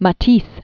(mə-tēs, mä-), Henri 1869-1954.